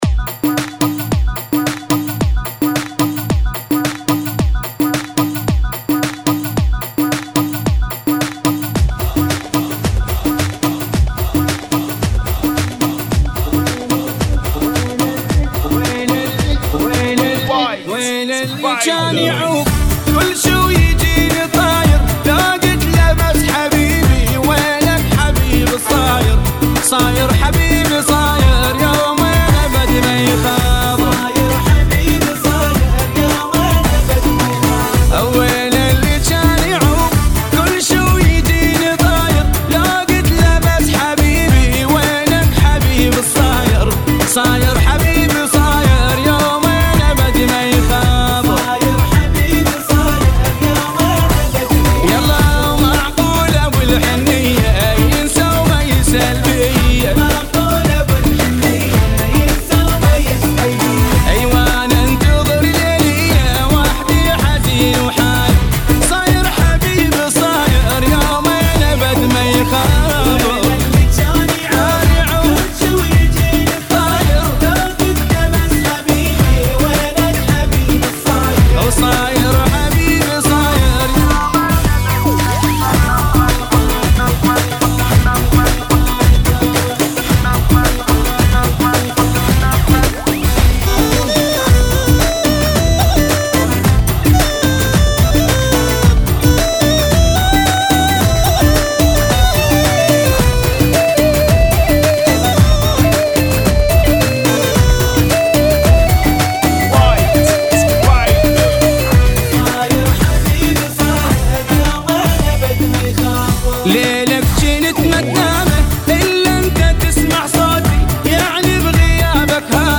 Funky [ 110 Bpm ]